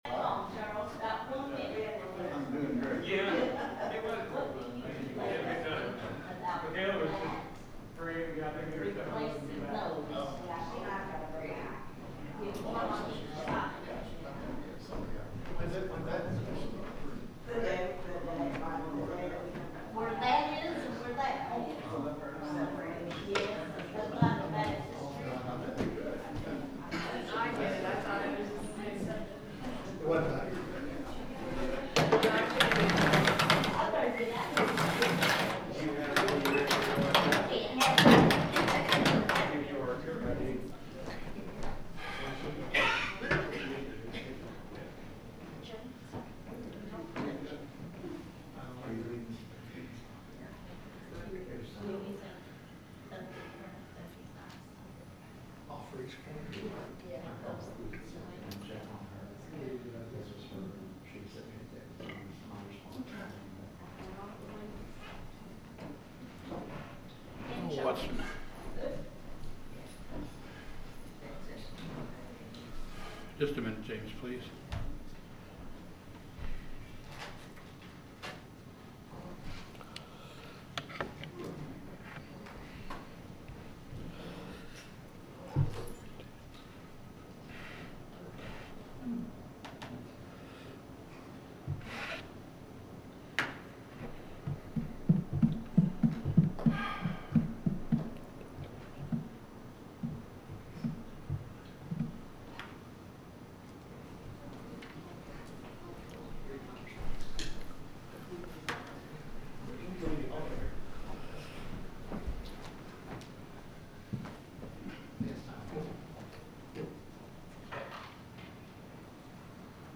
The sermon is from our live stream on 7/13/2025